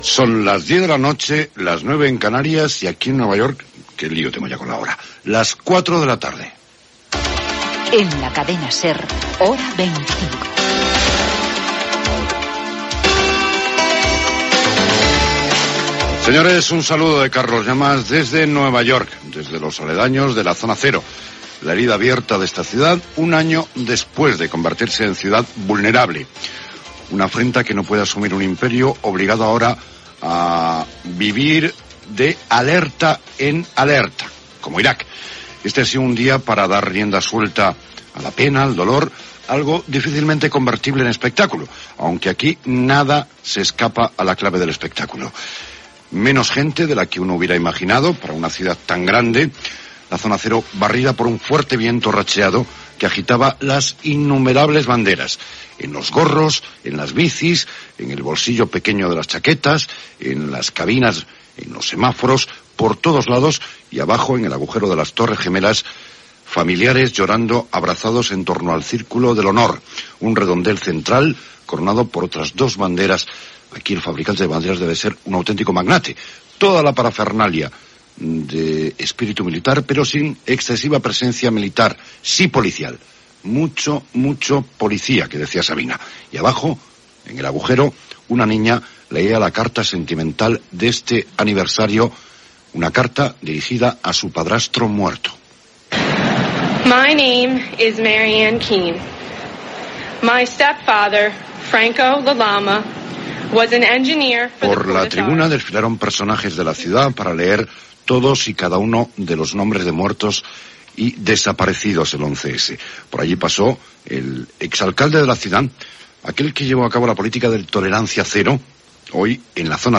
Resum informatiu, esports, publicitat i indicatiu. Informació de l'acte fet a la zona zero de Manhattan.